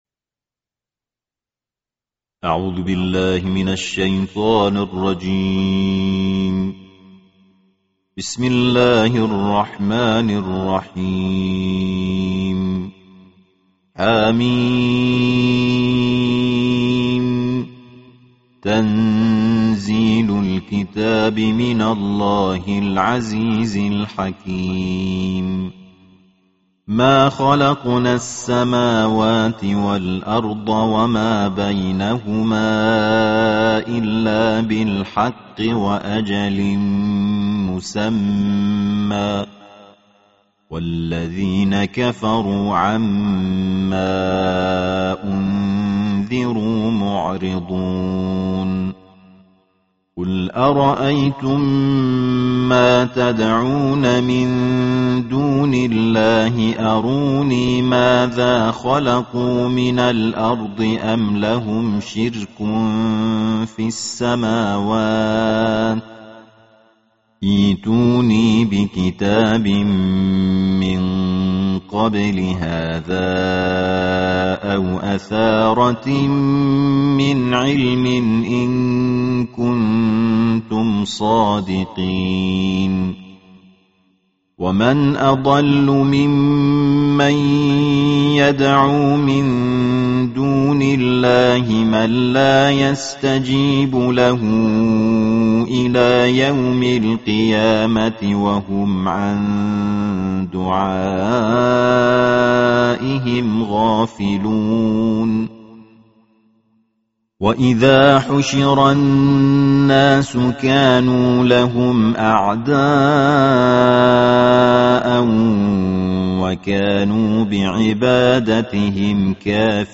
সুললিত কণ্ঠে ২৬তম পারার তিলাওয়াত